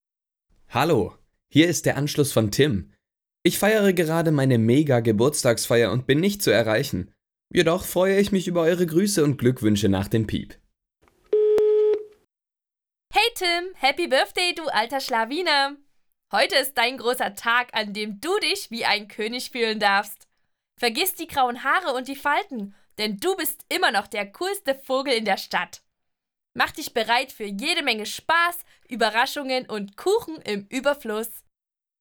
Durch den Einsatz hochwertiger Komponenten garantieren wir eine klare und störfreie Aufnahme der eingesprochenen Botschaften.
Audiogästebuch mieten - Sprachbeispiele:
Geburtstag:
Beispiel-Geburtstag.wav